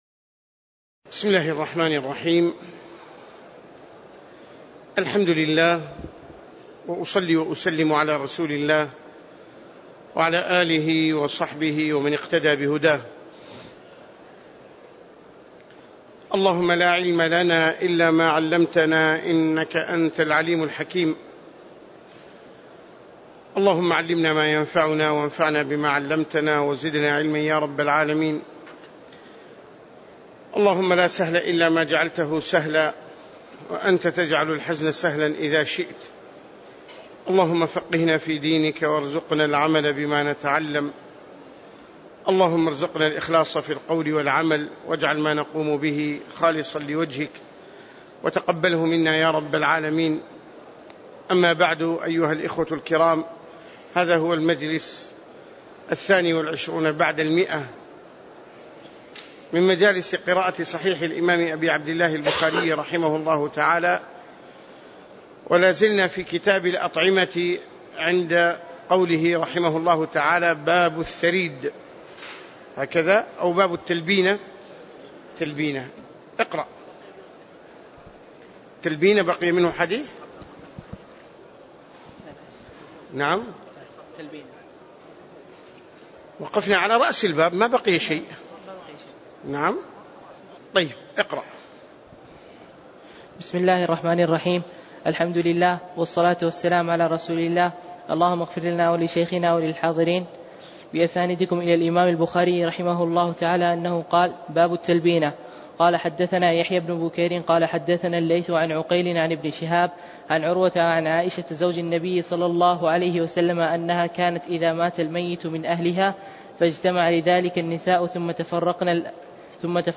تاريخ النشر ٢١ شعبان ١٤٣٨ هـ المكان: المسجد النبوي الشيخ